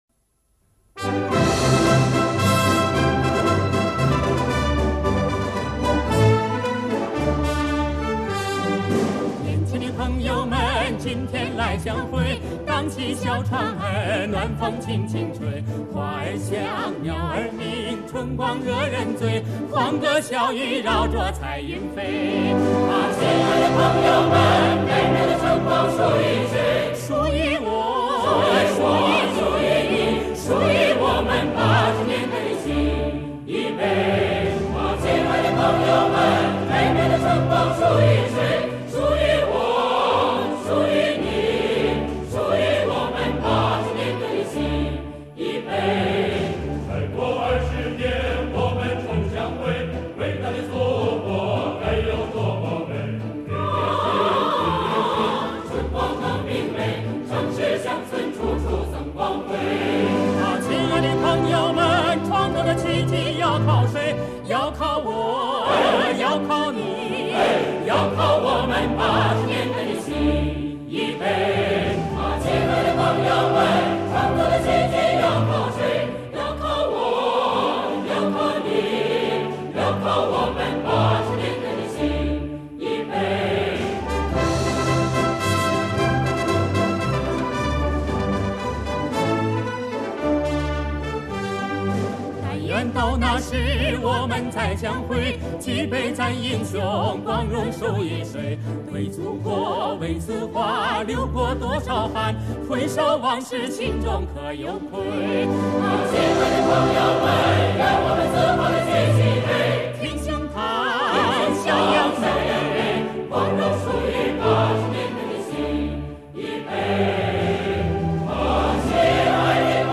珍贵的历史录音